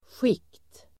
Uttal: [sjik:t]